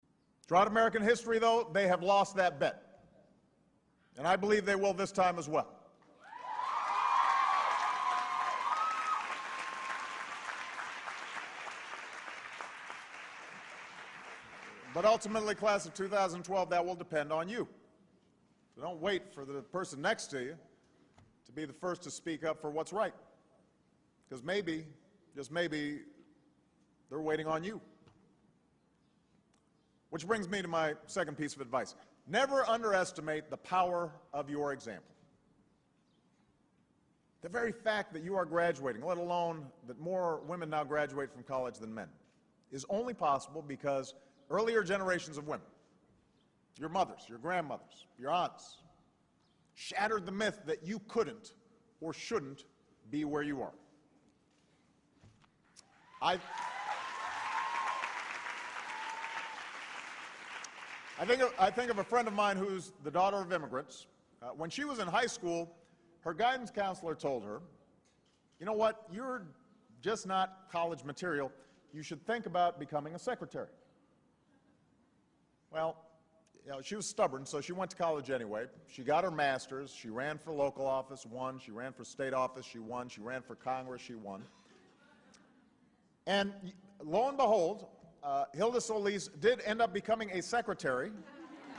公众人物毕业演讲第394期:奥巴马2012年哥伦比亚大学毕业演讲(12) 听力文件下载—在线英语听力室